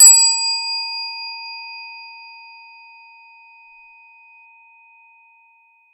bell ding metal windchime sound effect free sound royalty free Nature